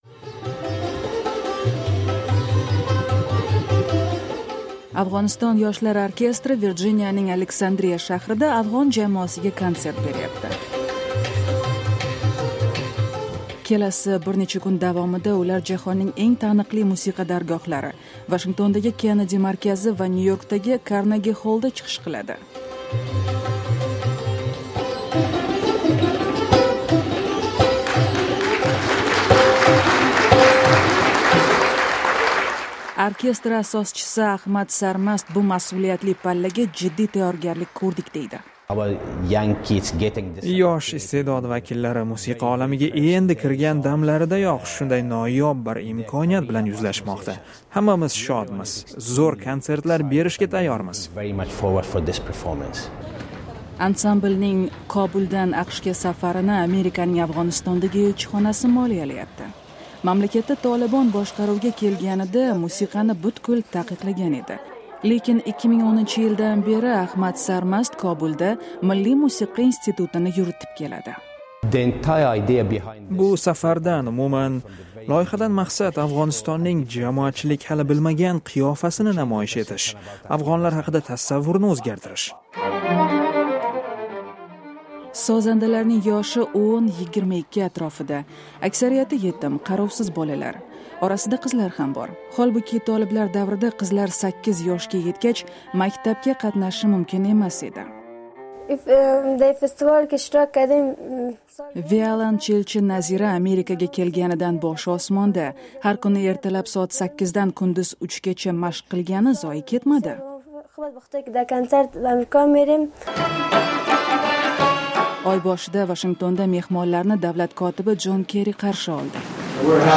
Afg’oniston yoshlar orkestri Virjiniyaning Aleksandriya shahrida afg’on jamoasiga konsert berayapti.